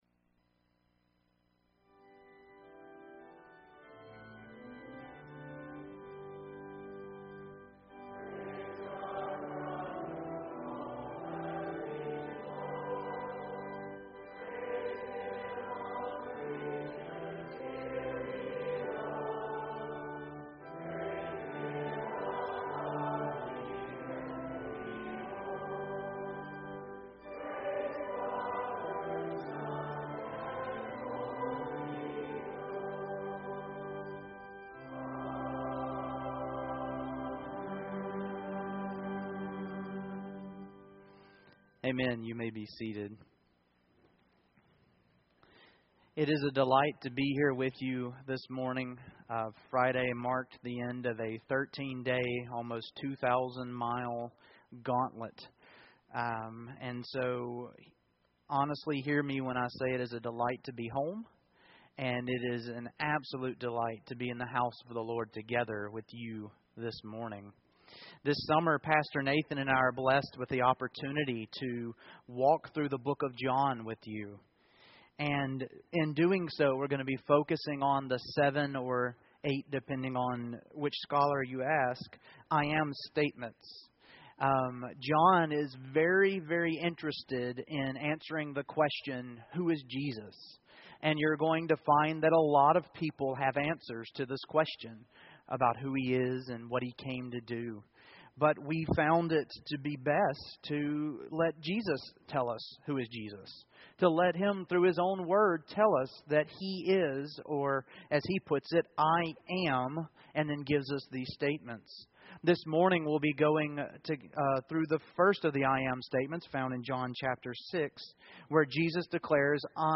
John 6:22-51 Service Type: Morning Worship I. Seeking Jesus